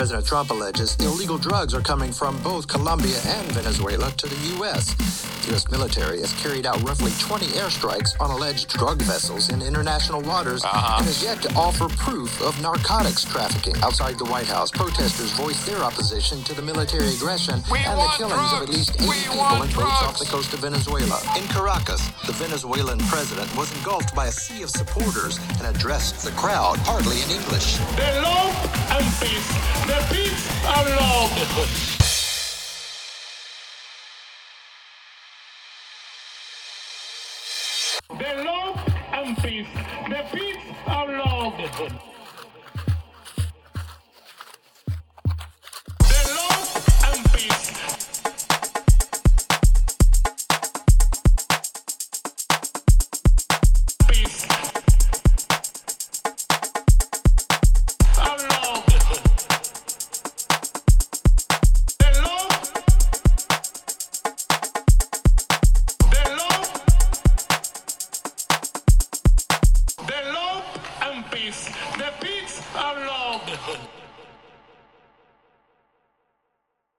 End of Show Mixes: